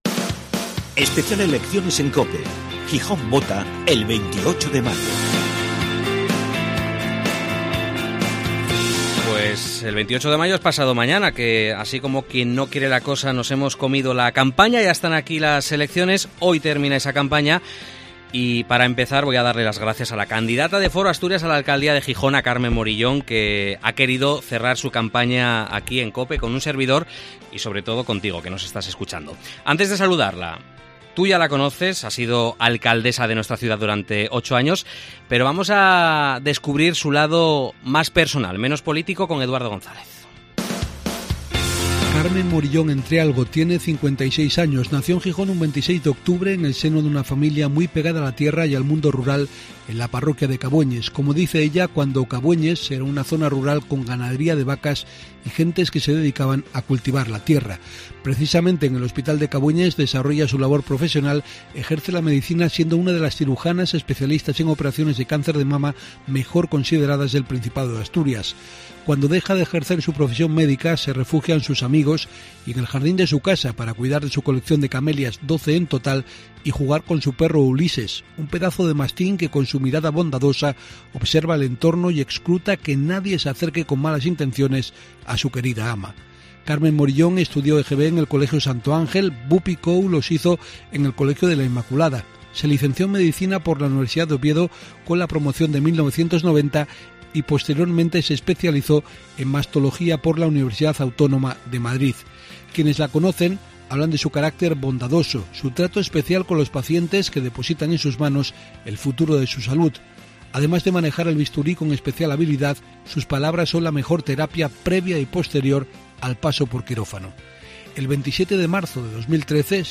Gijón está en campaña electoral. En COPE hablamos con los candidatos, pero también escuchamos a los ciudadanos, sus inquietudes y peticiones.